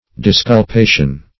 Disculpation \Dis`cul*pa"tion\, n.
disculpation.mp3